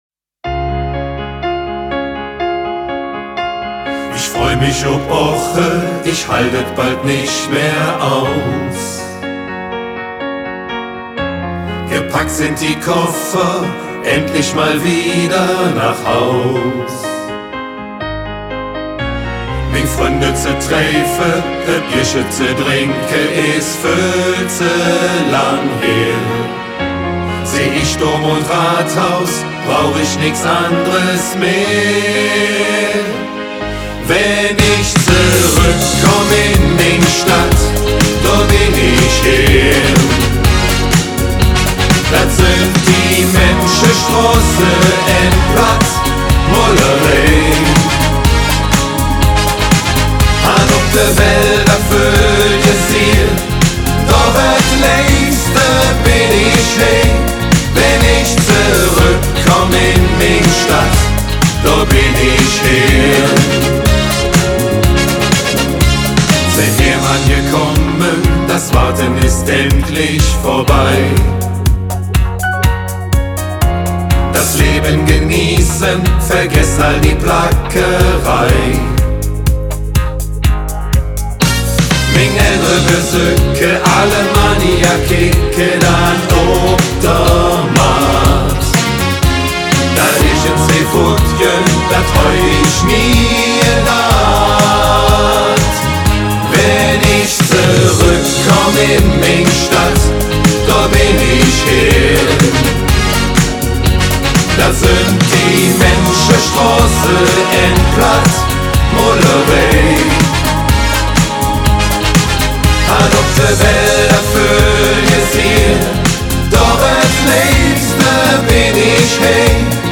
Wir waren im Studio und haben 3 neue Songs produziert.